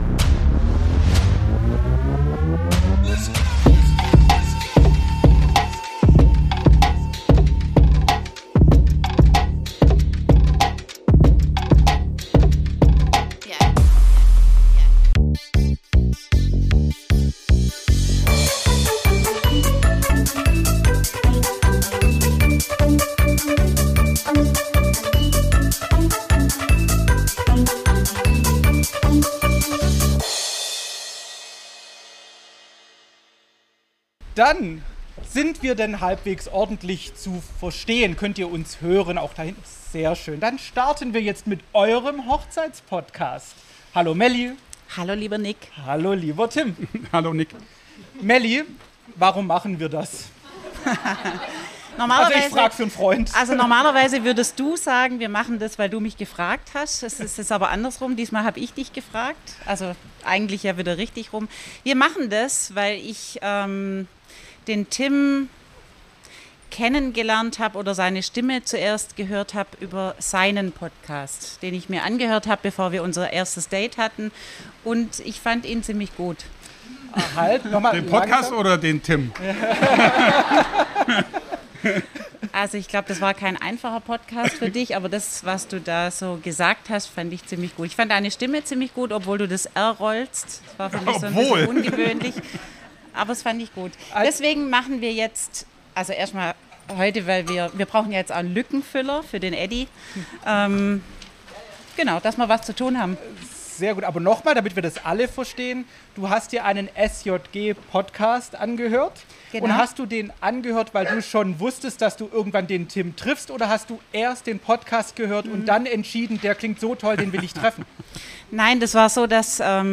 Live aus dem Zelt im Circuleum in Stuttgart treffen zwei Podcast-Welten aufeinander: FINKGezwitscher meets Stjg Laut! Es wurde laut, herzlich, bunt – und ganz schön romantisch.